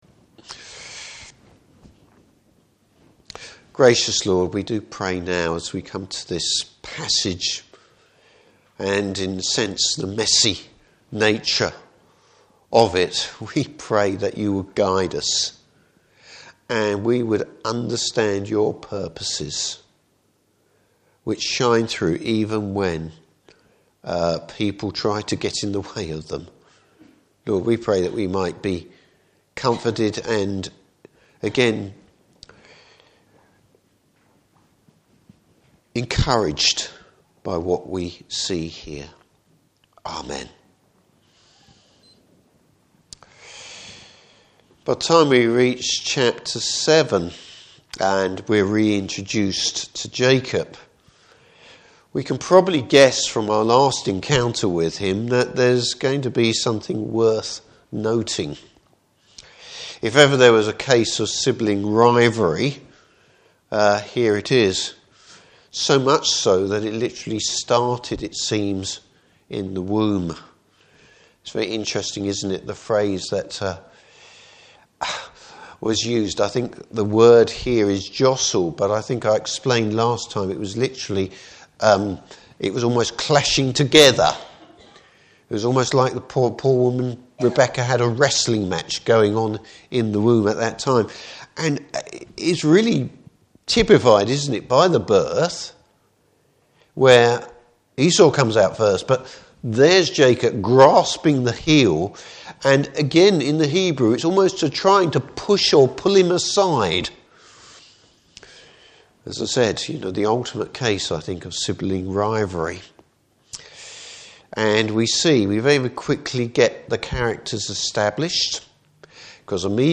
Service Type: Evening Service Jacob’s scheming reaches a new low!